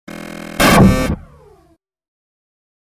Звуки зависания, сбоя программы
Звук аварийного отключения программы при зависании